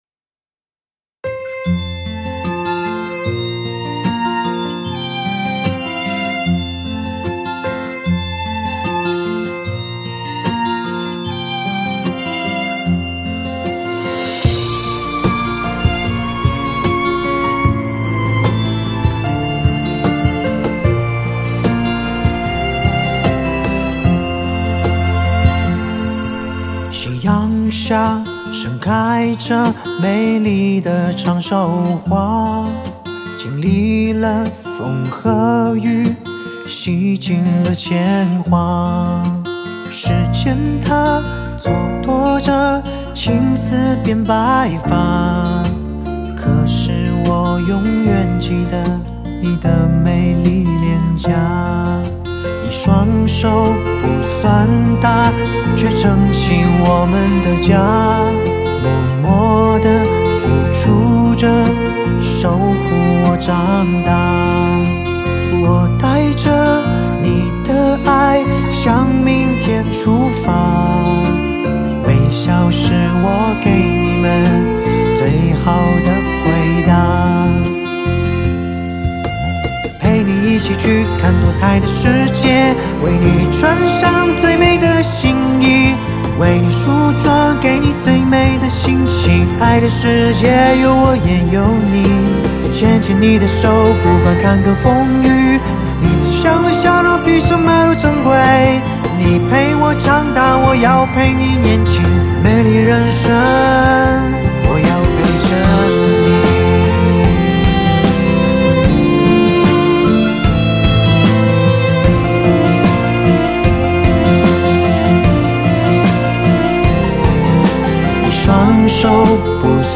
第十四屆阿公阿嬤節主題曲